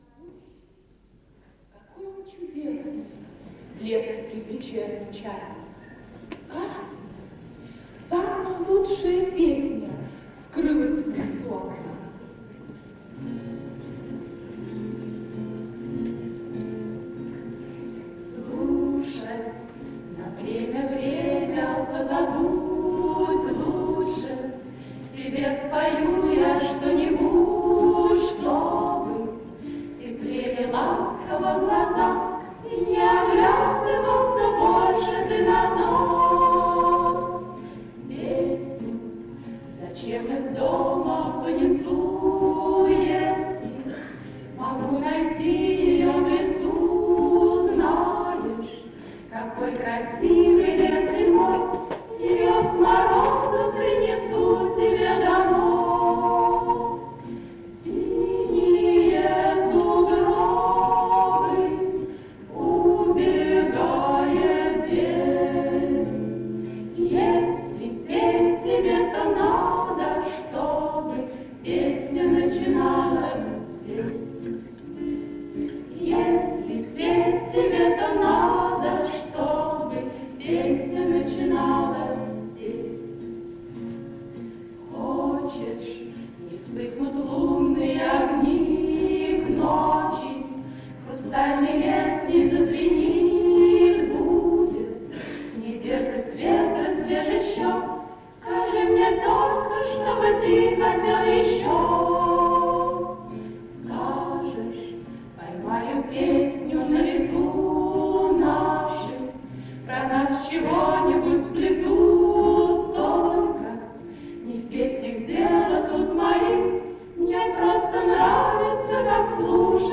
ВТОРОЙ МОСКОВСКИЙ ВЕЧЕР-КОНКУРС СТУДЕНЧЕСКОЙ ПЕСНИ
Октет МГПИ в составе